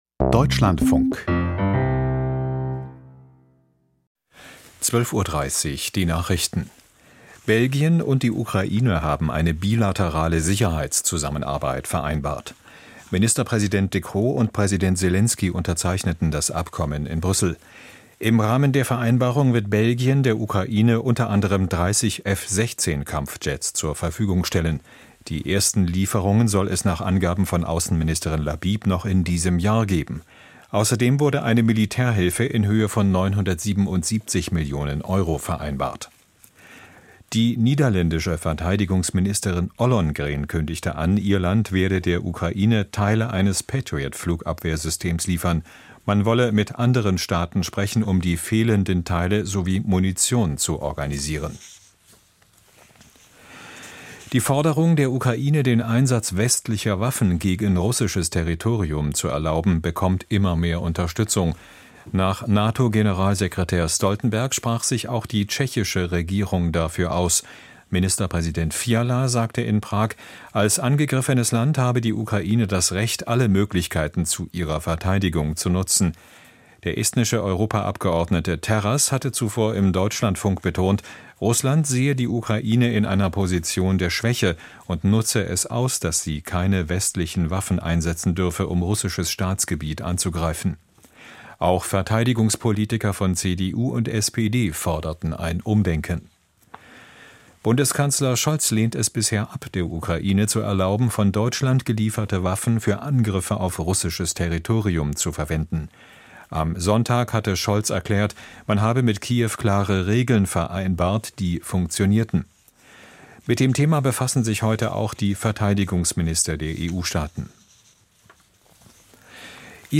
Droht Israel die Isolation? Interview mit jüdischem Publizisten Michel Friedman - 28.05.2024